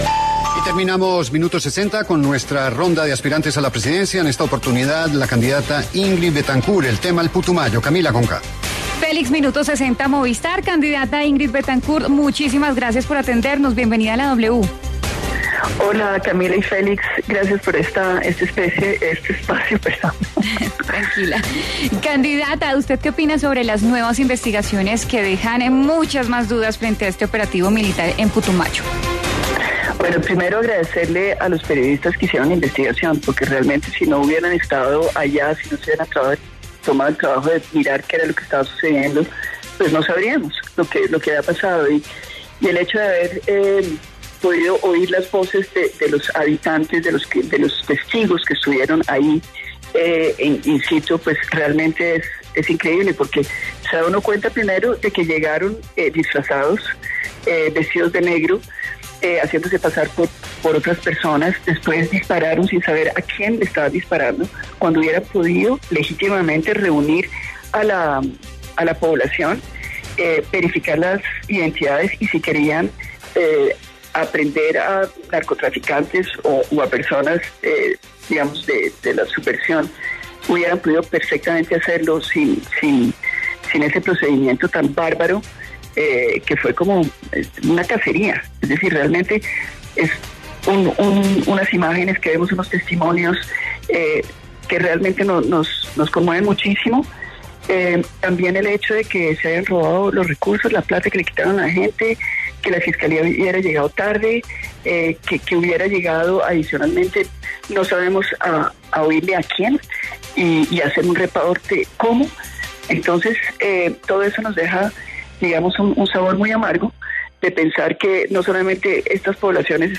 En diálogo con La W, la candidata presidencial Ingrid Betancourt opinó sobre el operativo militar en Putumayo que ha sido fuertemente cuestionado.